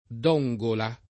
Dunqula [ar. d 2jM ula ] top. (Sudan) — adatt. come Dongola [it. d 0jg ola ]